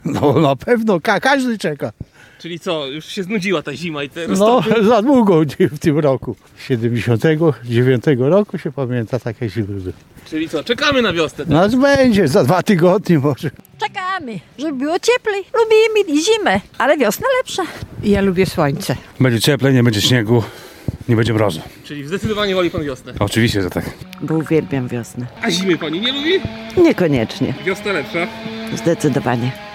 Sonda: Mieszkańcy Łomży czekają na wiosnę
Odpowiedzi, które usłyszał nasz reporter, nie pozostawiają wątpliwości.